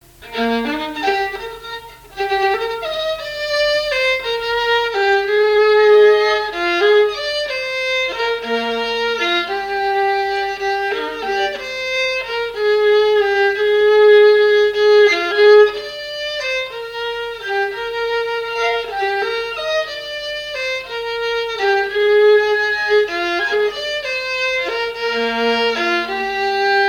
danse : valse
Le quadrille et danses de salons au violon
Pièce musicale inédite